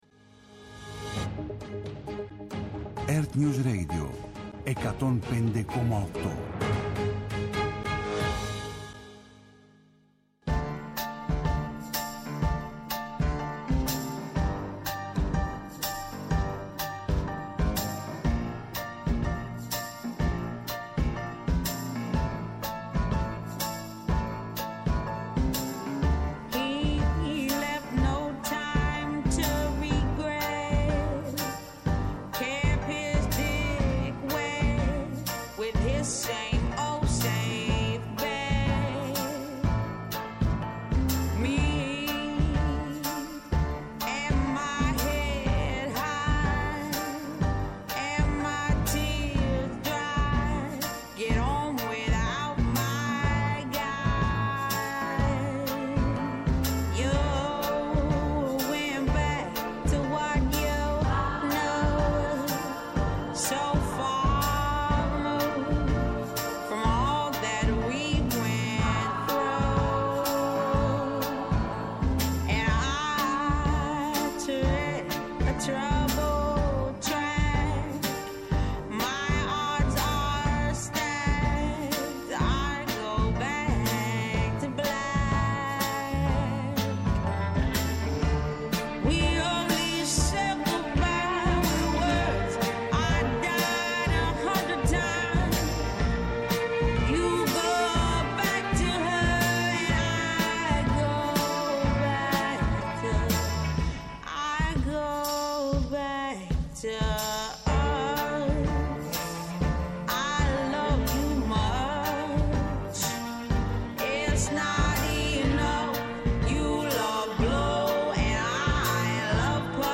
-Απόσπασμα από την ενημέρωση των πολιτικών συντακτών, από τον κυβερνητικό εκπρόσωπο, Παύλο Μαρινάκη